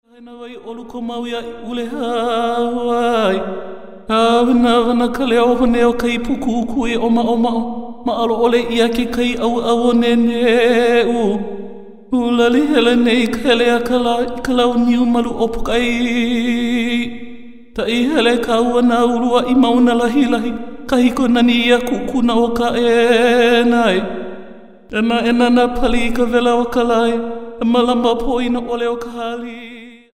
• Genre: Traditional Hawaiian.
falsetto